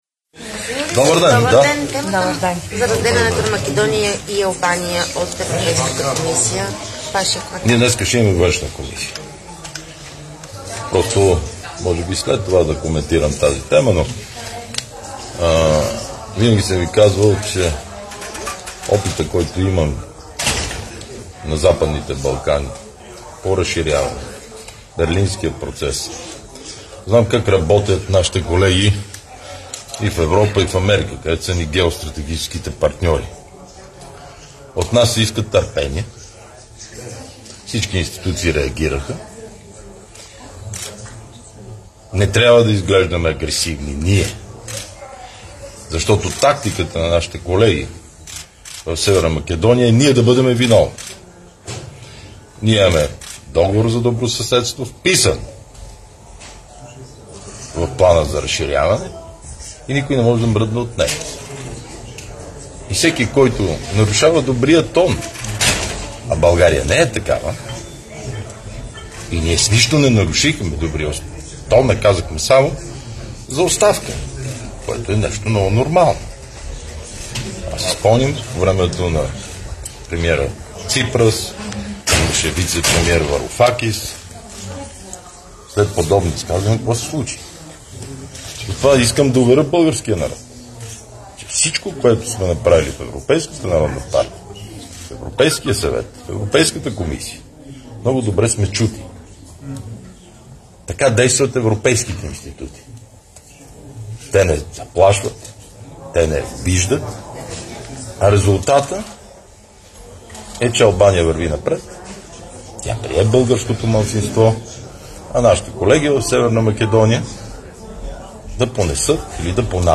9.05 - Заседание на Народното събрание.
- директно от мястото на събитието (Народно събрание)
Директно от мястото на събитието